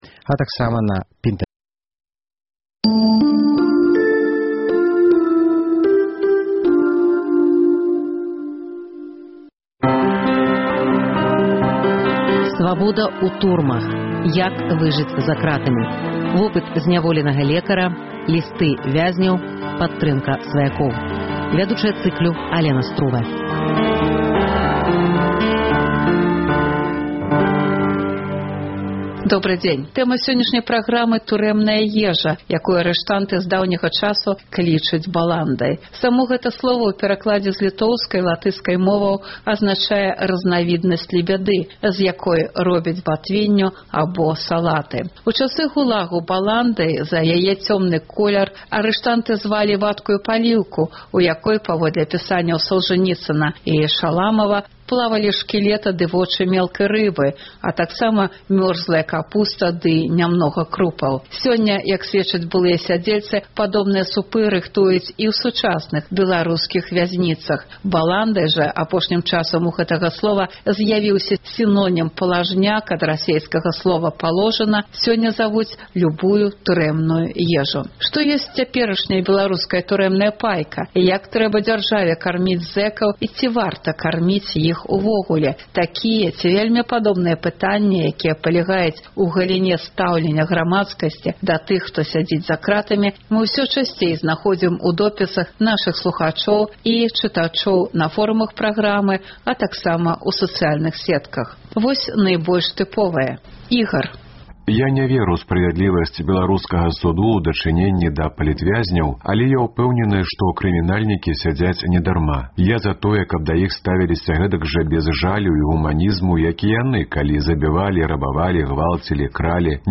Сёлета на Дзень Волі на сайце Свабоды — жывы рэпартаж зь мітынгу і шэсьця ў Менску.